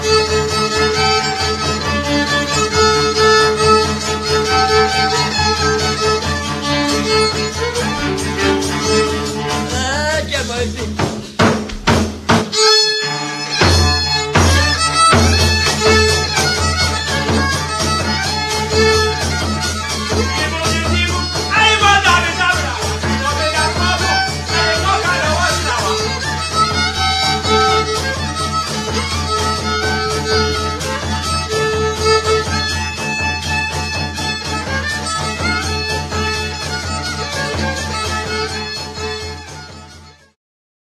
skrzypce
basy 3-strunowe
bębenek, śpiew